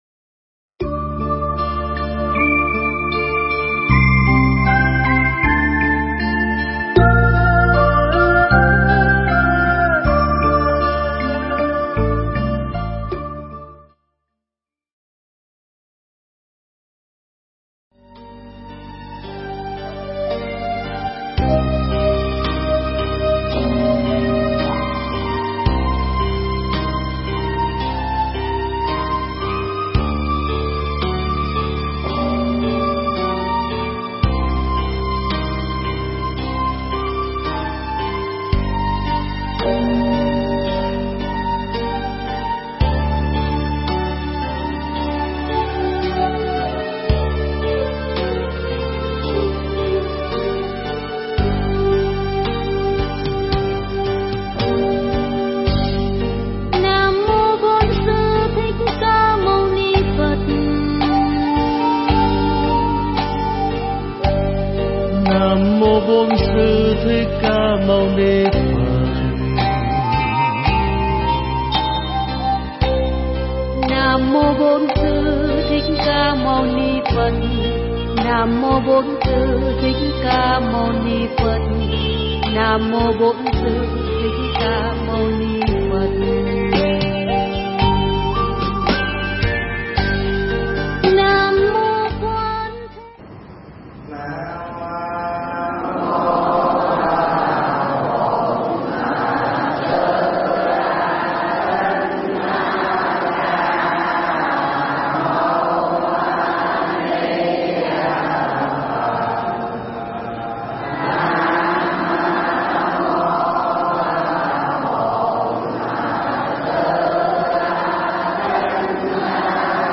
Nghe Mp3 thuyết pháp Lời Thầy Khuyên Tu
Nghe m3p pháp thoại Lời Thầy Khuyên Tu